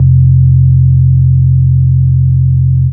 Subnormal Bass 65-08.wav